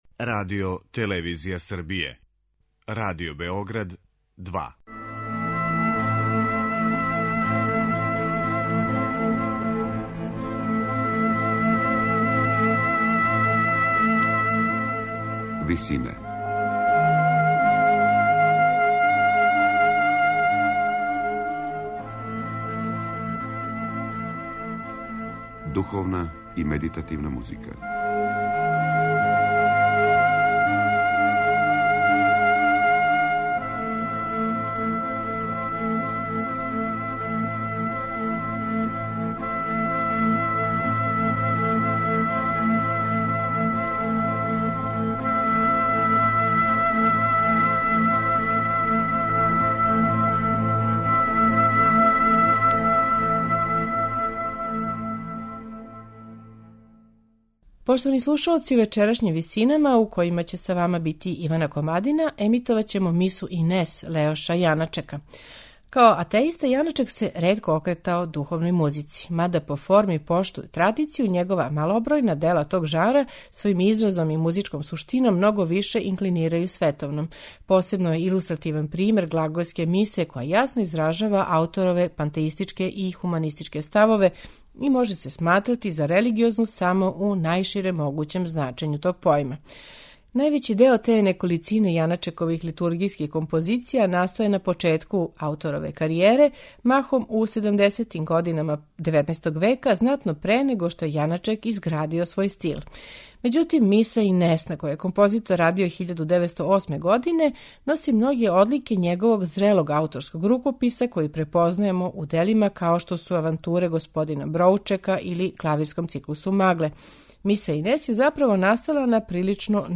духовној музици
оргуљаша
медитативне и духовне композиције